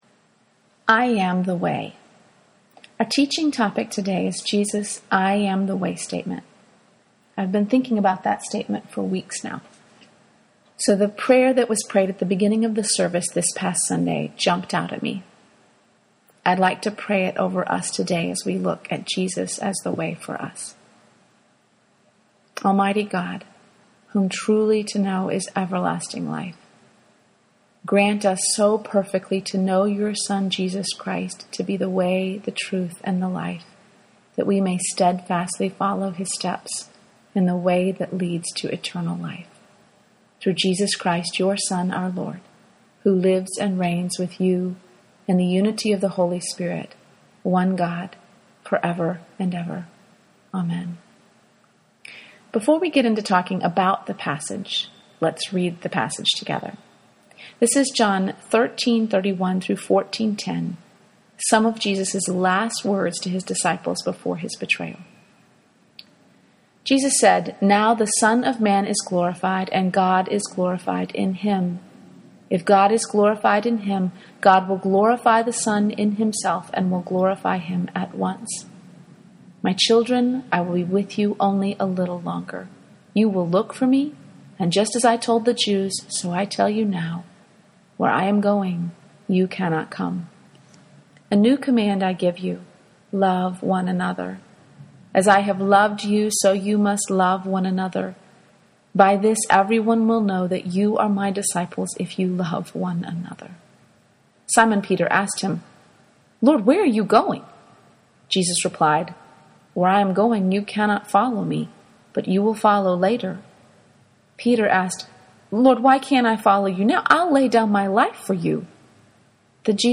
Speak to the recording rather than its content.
In the meantime, I’d like to share a teaching that I gave at our women’s Bible study a couple weeks ago.